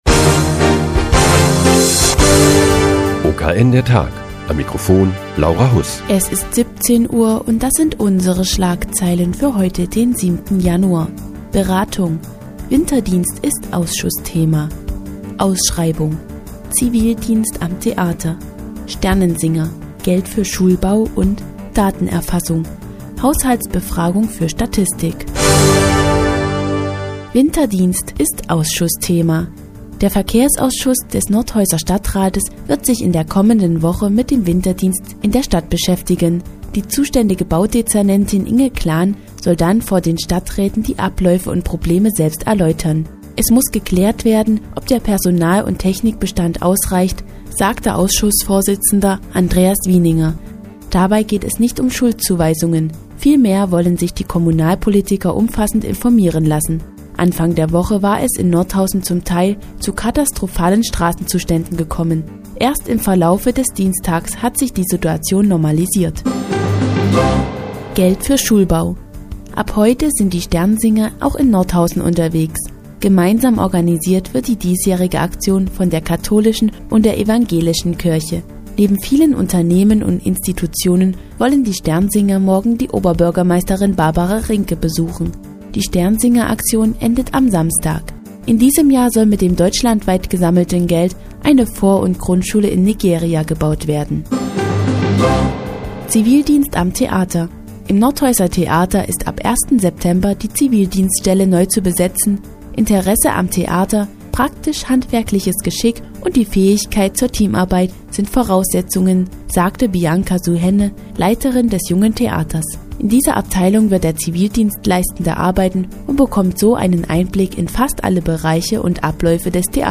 Die tägliche Nachrichtensendung des OKN ist nun auch in der nnz zu hören. Heute geht es um die Sternsingeraktion für einen guten Zweck und die Zivildienststelle am Nordhäuser Theater.